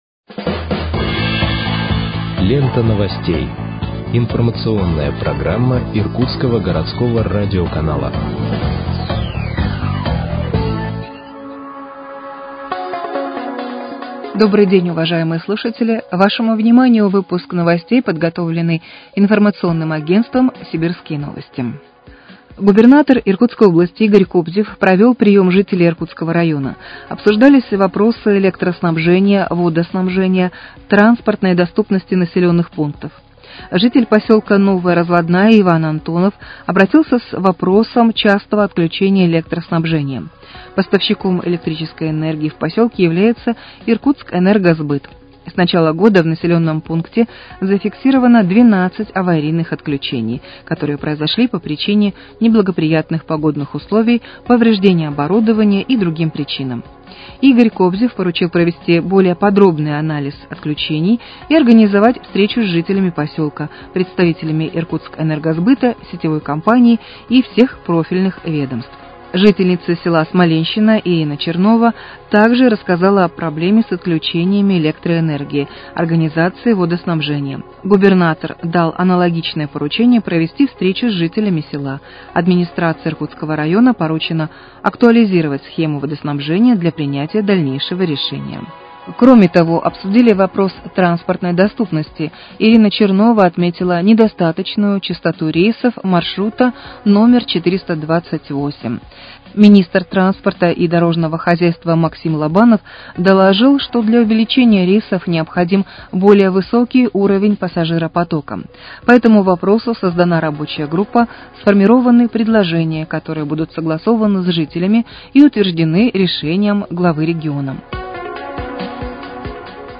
Выпуск новостей в подкастах газеты «Иркутск» от 14.08.2025 № 2